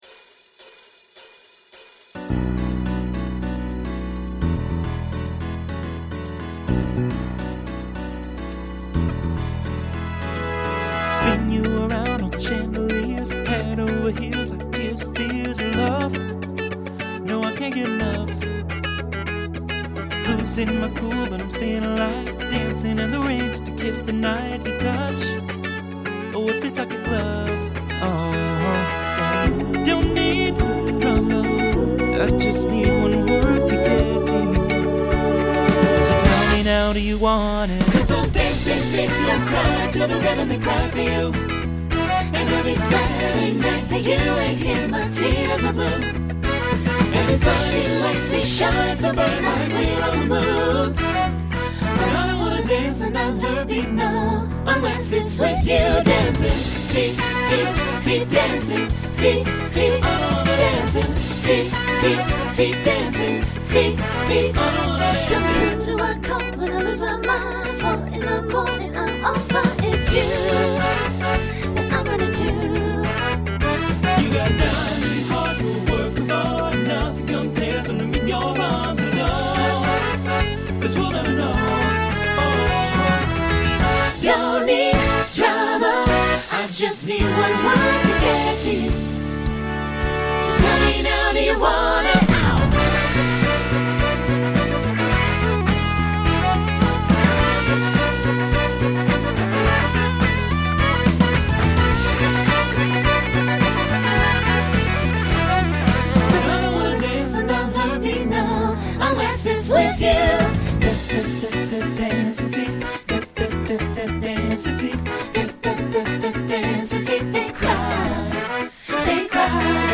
Genre Pop/Dance
Mid-tempo Voicing SATB